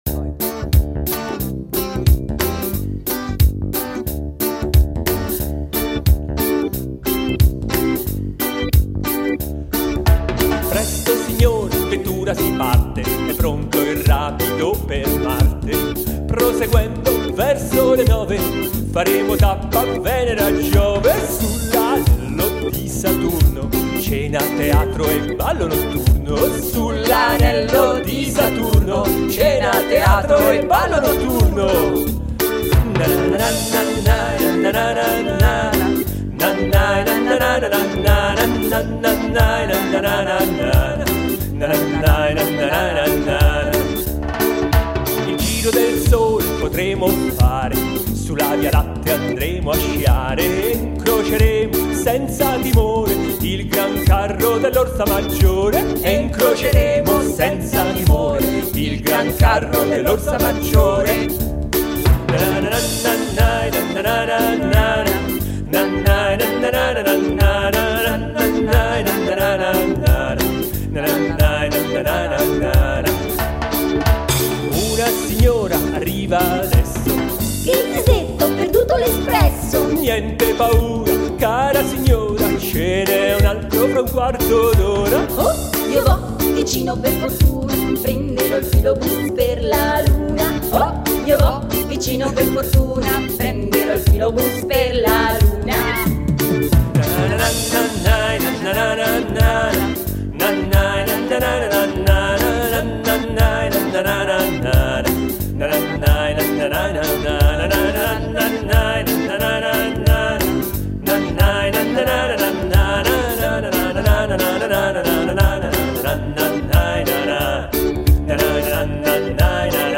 nei cori e nel nanana
Invece quando parla la vecchia signora è una collega del mio ufficio